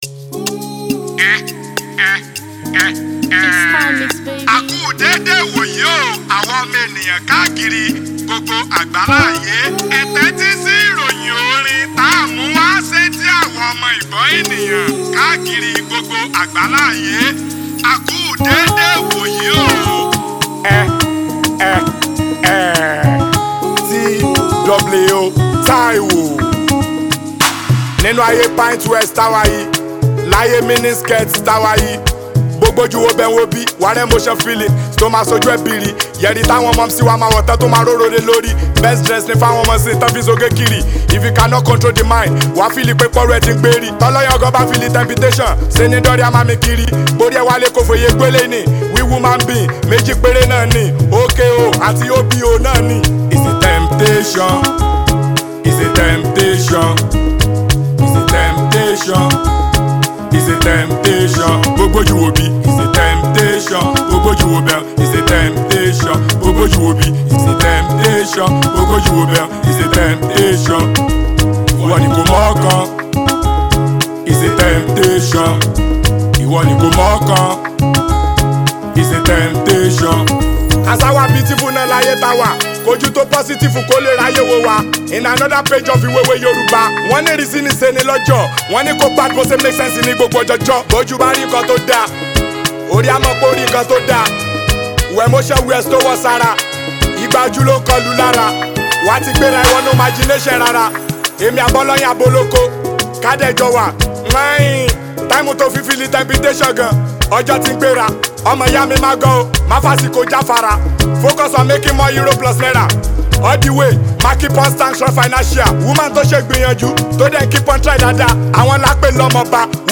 smooth, emotionally layered track
vocal delivery carries both confidence and restraint